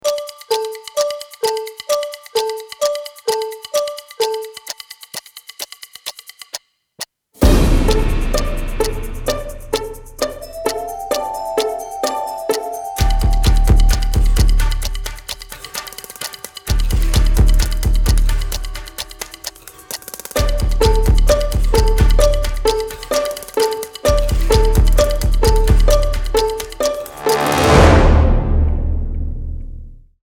30 sec clock